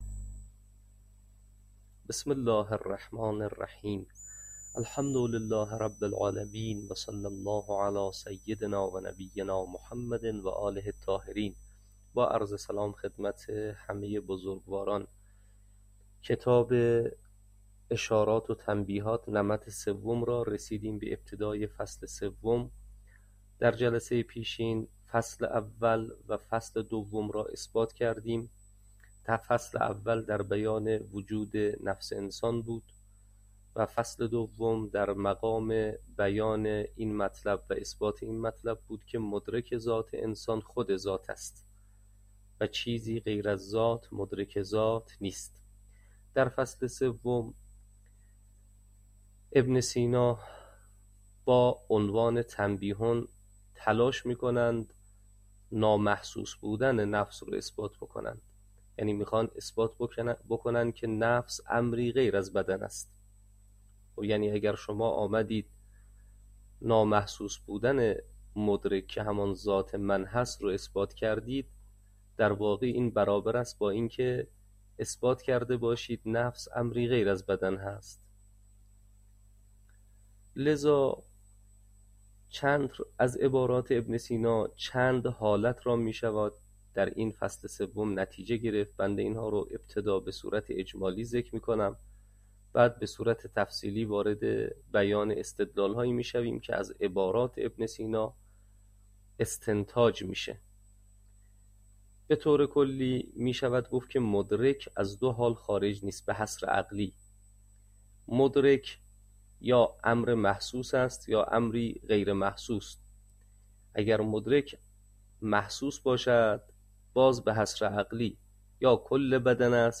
شرح اشارات و تنبیهات، تدریس